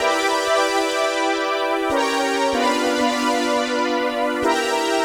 AM_VictorPad_95-E.wav